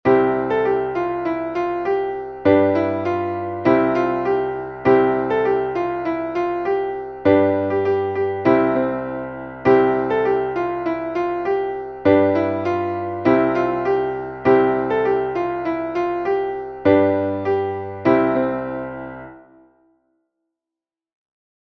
Traditionelles Herbstlied